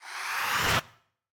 Minecraft Version Minecraft Version 1.21.5 Latest Release | Latest Snapshot 1.21.5 / assets / minecraft / sounds / mob / breeze / inhale1.ogg Compare With Compare With Latest Release | Latest Snapshot
inhale1.ogg